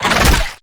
Sfx_creature_rockpuncher_chase_os_04.ogg